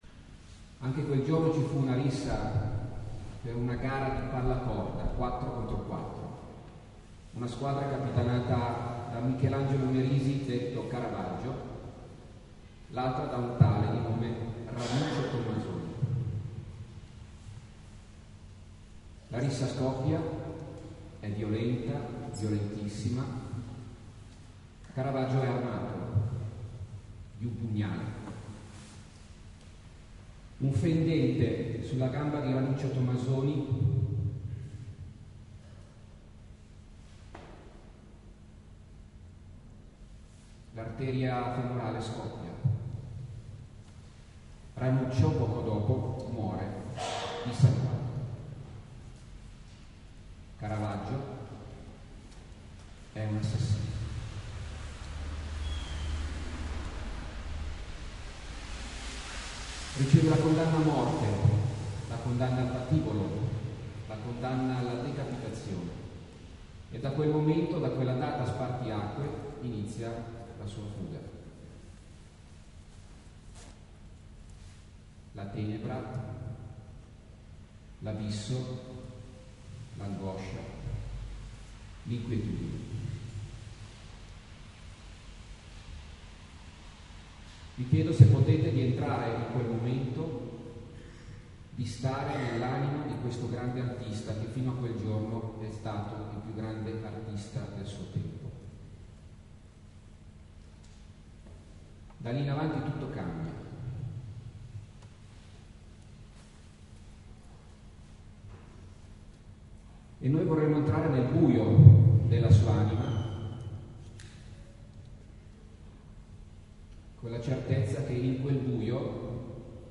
Preghiera quaresimale attraverso l’arte in Santuario: CARAVAGGIO OMBRE E LUCI “Troppo umano, ma divino”.
Audio commento ai dipinti